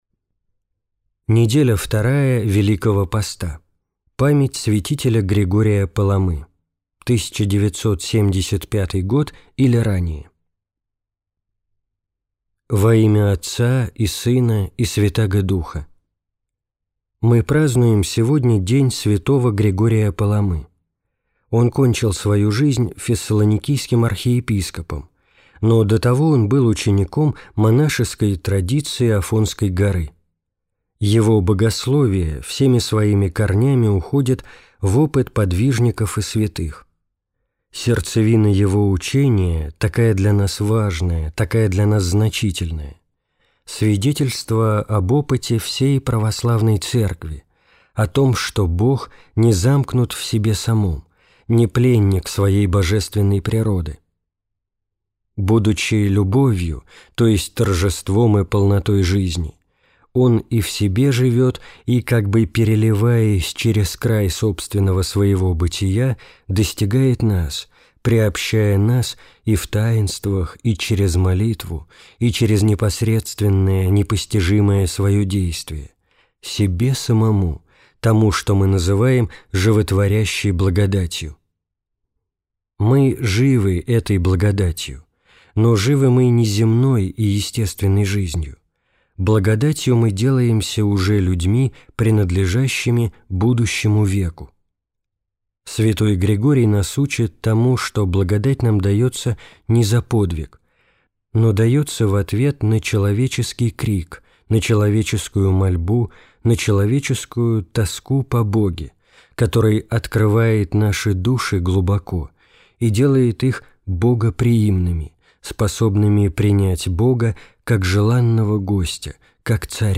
Проповедь митрополита Сурожского Антония в неделю 2-ю Великого поста, память святителя Григория Паламы.